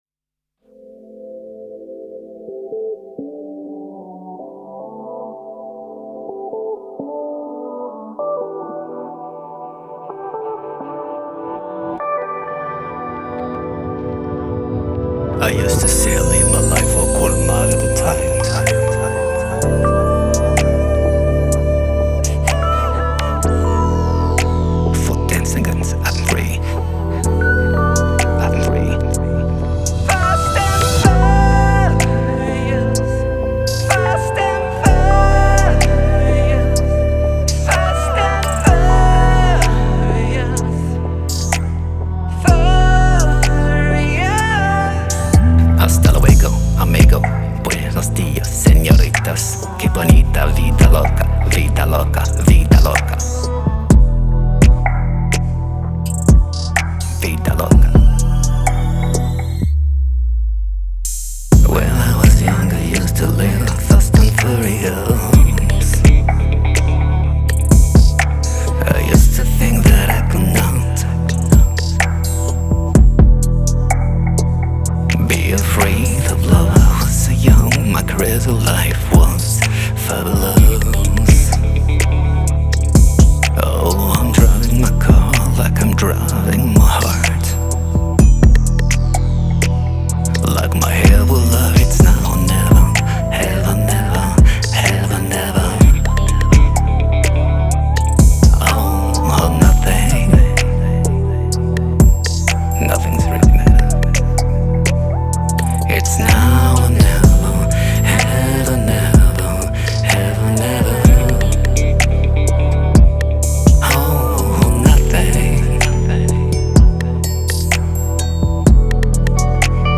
13. R&B version